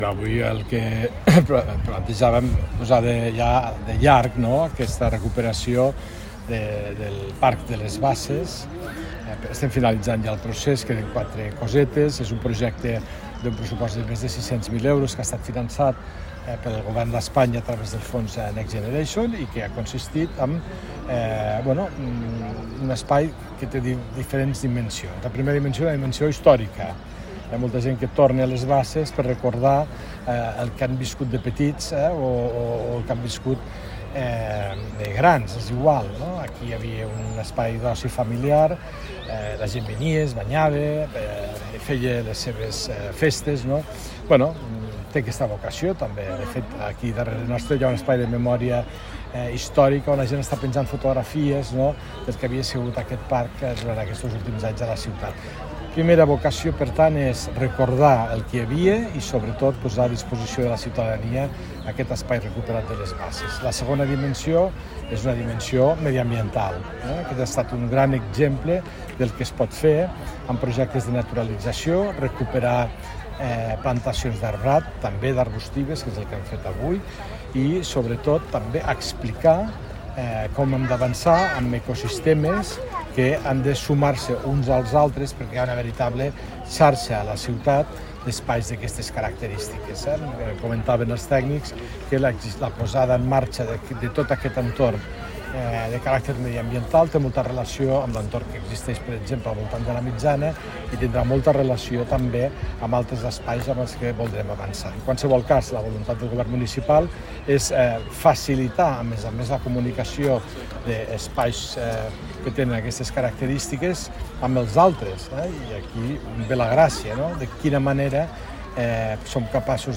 L’alcalde de Lleida, Fèlix Larrosa, que ha participat en la diada, ha destacat la dimensió històrica, mediambiental, social, educativa i cívica del Parc